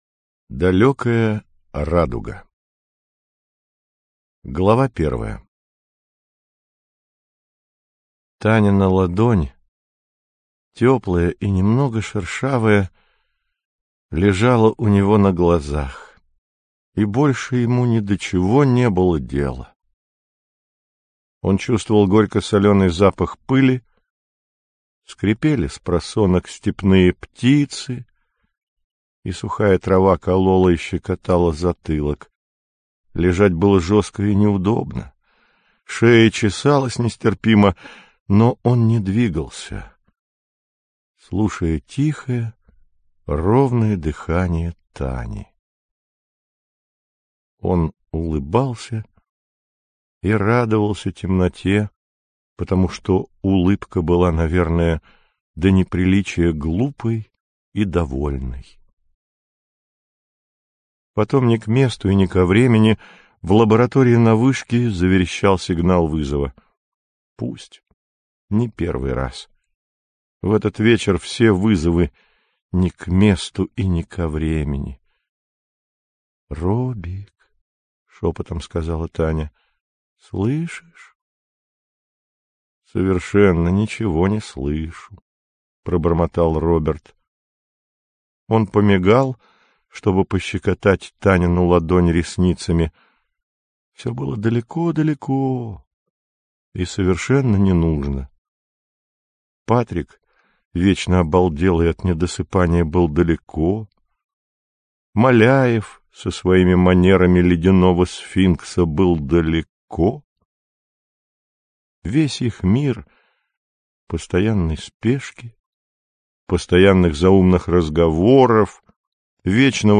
Аудиокнига Далёкая Радуга - купить, скачать и слушать онлайн | КнигоПоиск